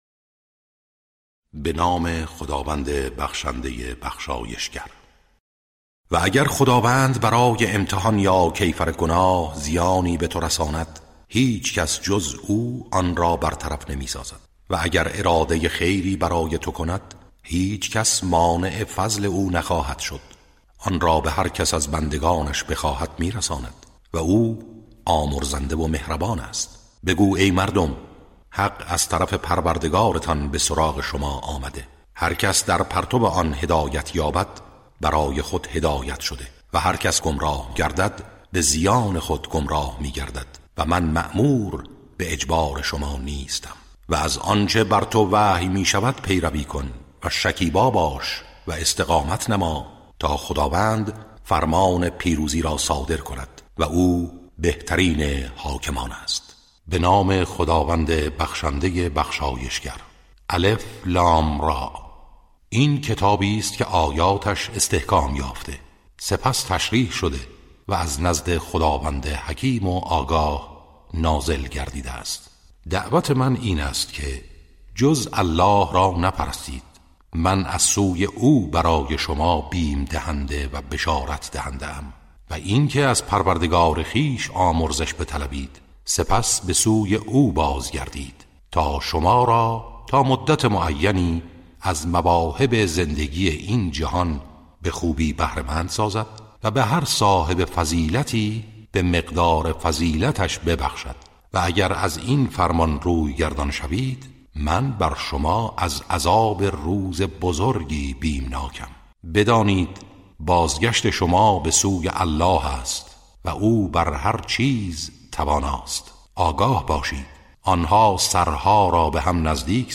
ترتیل صفحه ۲۲۱ سوره مبارکه یونس و هود(جزء یازدهم)